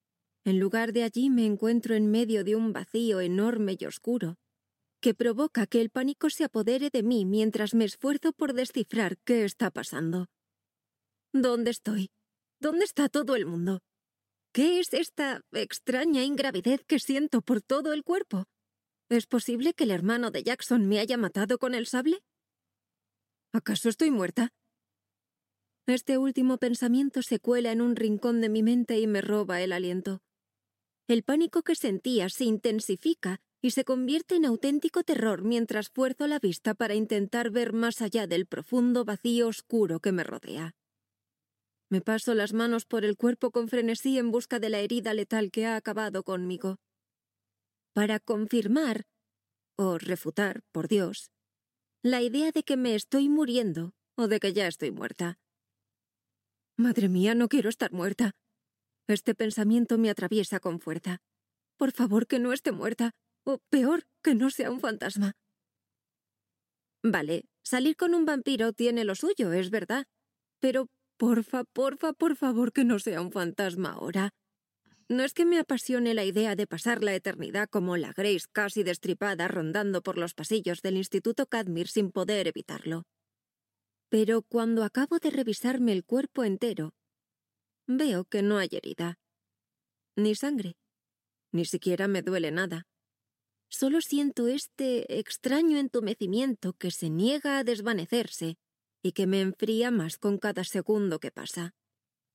Mi voz se adapta al ritmo y estilo de cada historia, creando una narrativa cautivadora.
Fragmentos de algunas narraciones de libros: